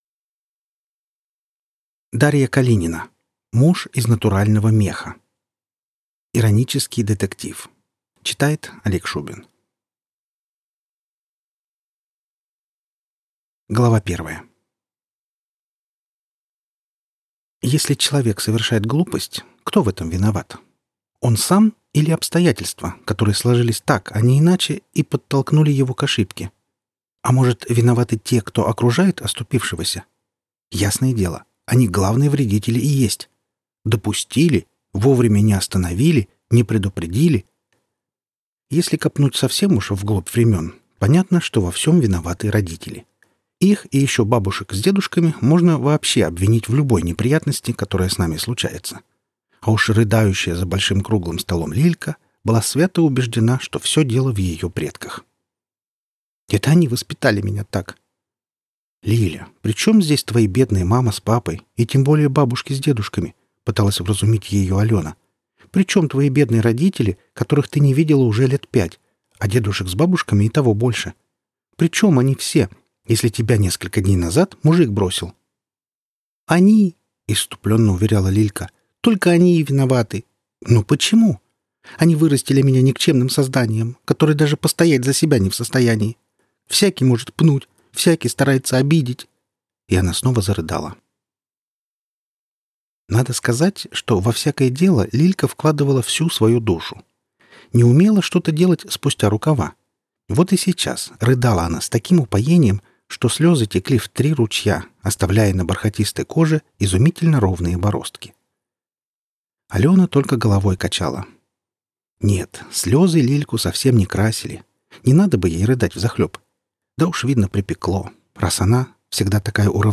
Аудиокнига Муж из натурального меха | Библиотека аудиокниг
Прослушать и бесплатно скачать фрагмент аудиокниги